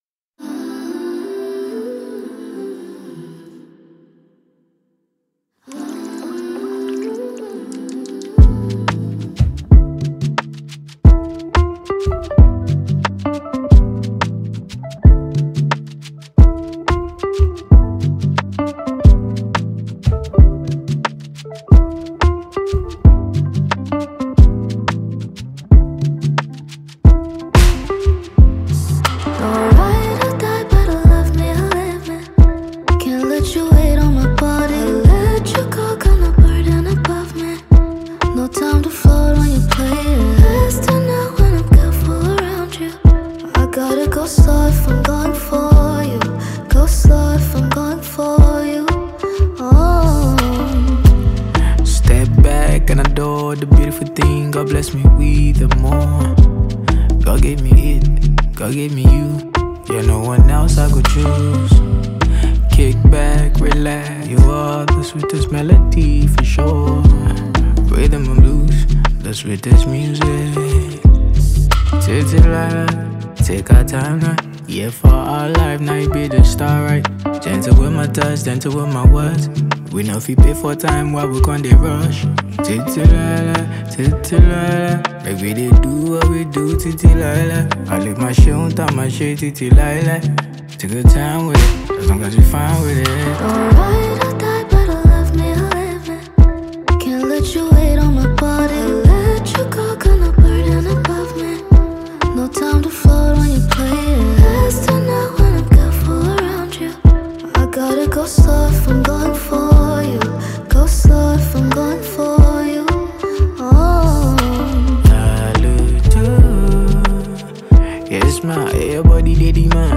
Grammy Award winning Nigerian heavyweight Afrobeat Singer
studio Album